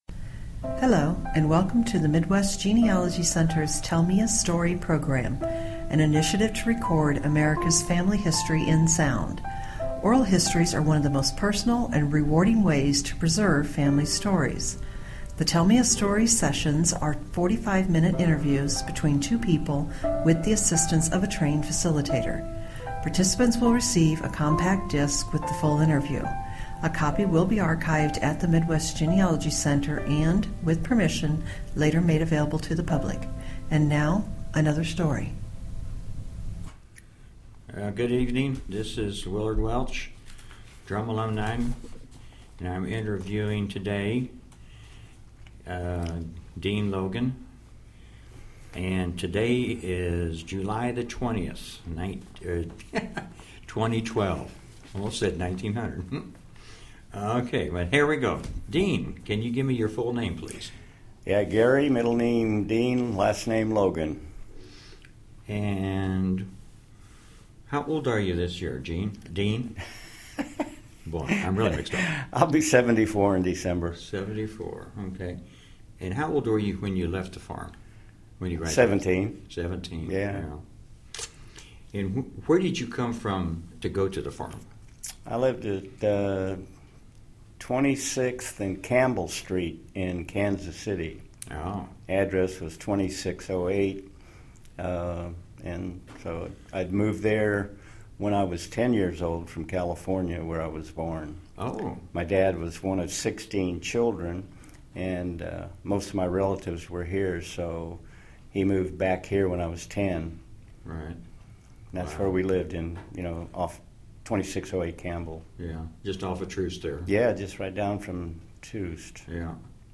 Drumm Institute Oral Histories